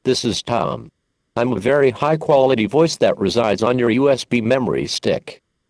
We also include 2 very high quality SAPI-5 compliant voices: RealSpeak